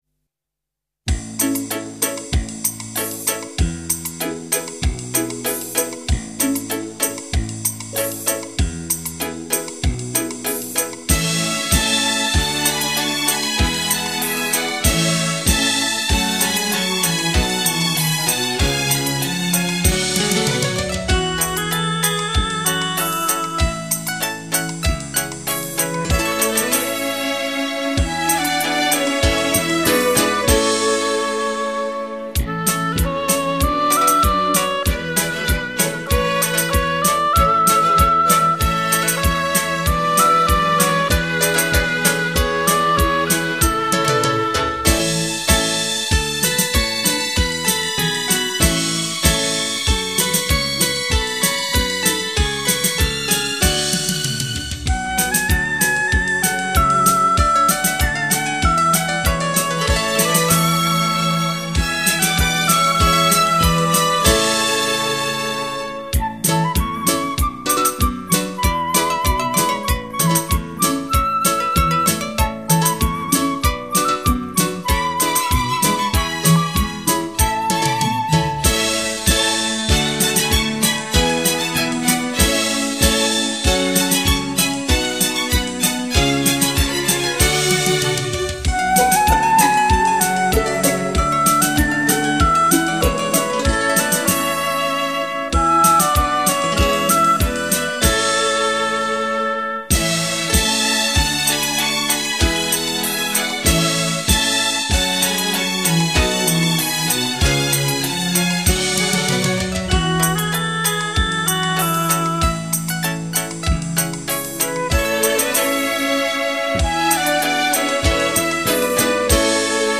国乐/民族
新疆音乐蓬勃舒展，直抒胸臆，热烈绮丽，太阳般光辉明朗，壮美绚丽。
改编为西洋管弦乐与中国民族乐器混合而成的轻音乐。
最新数码录音，令听者仿佛置身于天山碧野，葡萄架下，不由闻乐起舞。